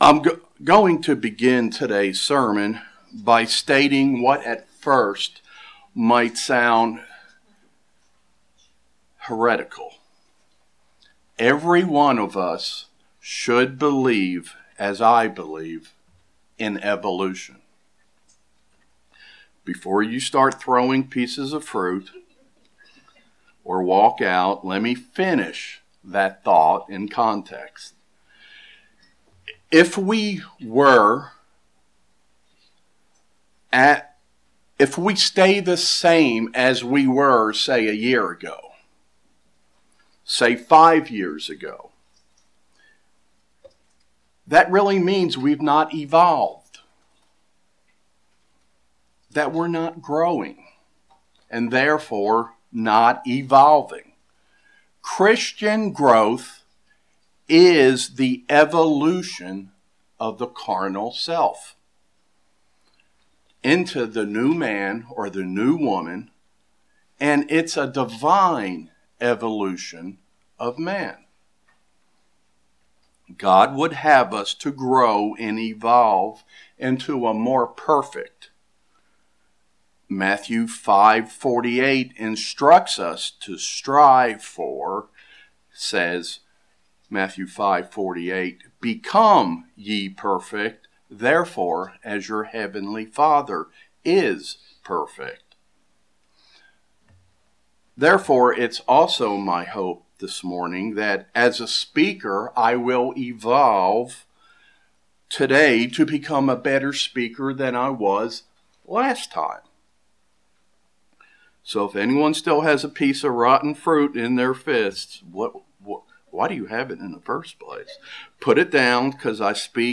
Sermons
Given in Kingsport, TN